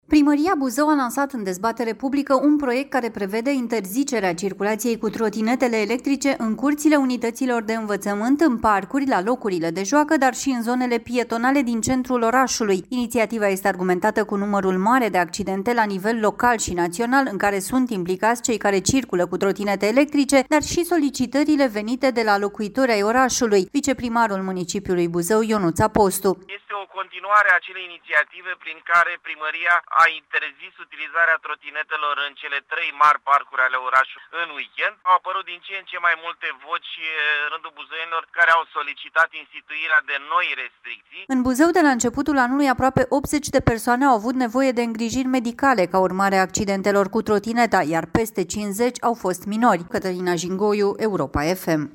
Viceprimarul municipiului Buzău, Ionuț Apostu: „Au apărut din ce în ce mai multe voci în rândul buzoienilor care au solicitat instituirea de noi restricții”